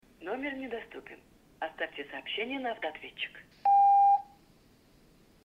Звук пустого номера